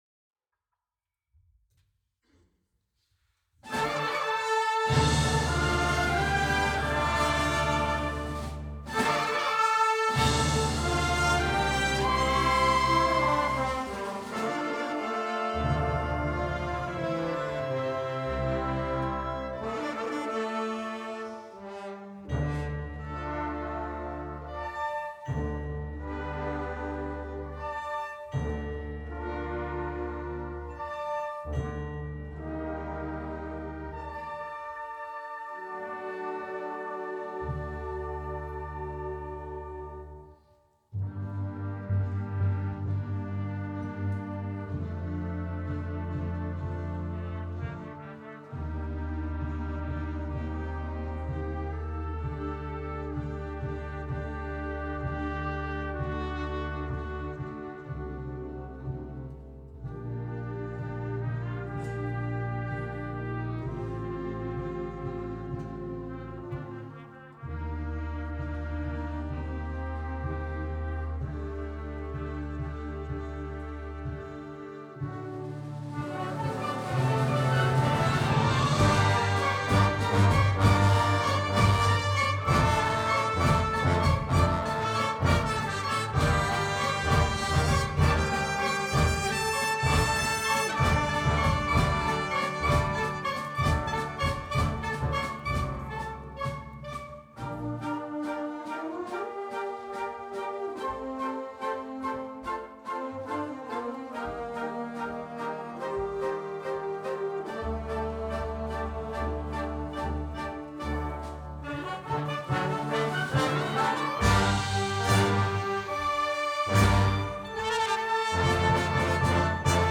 Ecco alcuni dei brani eseguiti più di frequente negli ultimi anni e alcune registrazioni dal vivo.